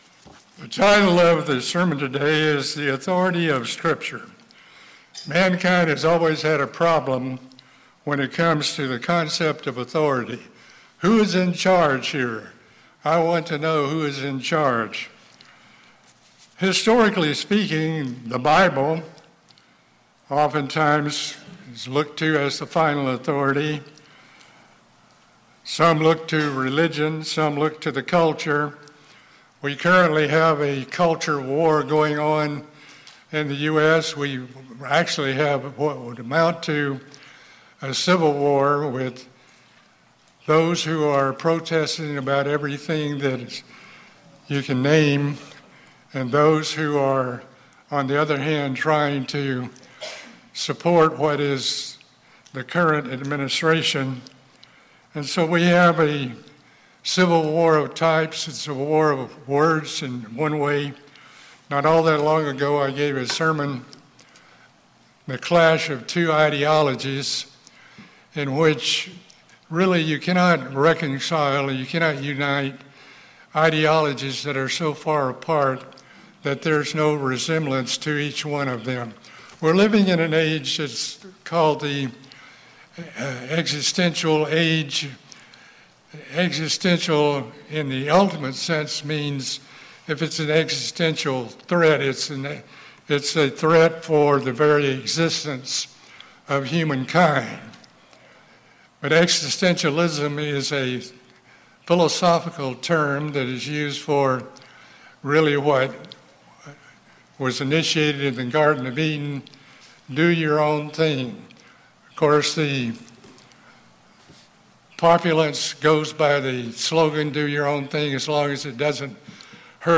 The sermon explores how Christ related to scripture and its importance. Scripture reveals the mind and will of God and Christ.